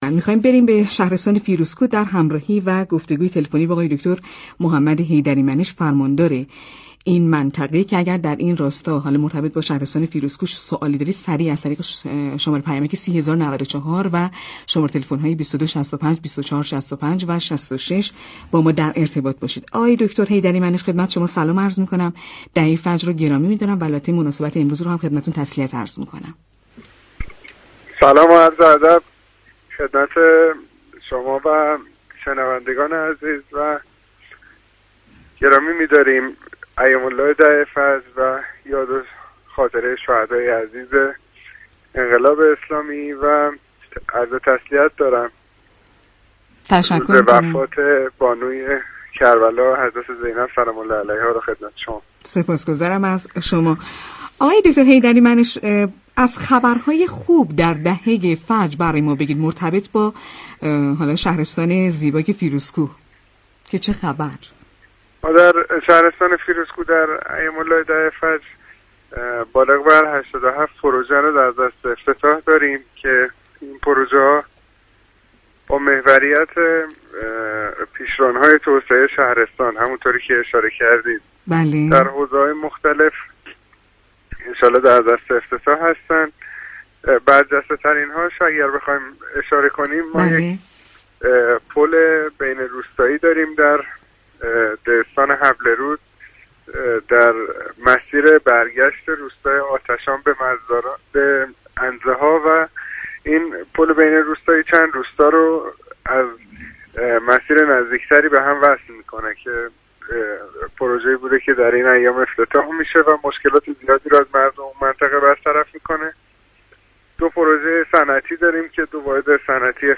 به گزارش پایگاه اطلاع رسانی رادیو تهران، محمد حیدری منش فرماندار شهرستان فیروزكوه در گفت و گو با «شهر آفتاب» درخصوص افتتاح و یا كلنگ‌زنی پروژهای عمرانی، صنعتی و خدماتی همزمان با دهه فجر در این شهرستان اظهار داشت: یكی از پروژه‌های دردست افتتاح در شهرستان فیروزكوه پل بین روستاهای دهستان حبله‌رود است كه روستاهای انزاها و آتشان را به هم متصل خواهد كرد.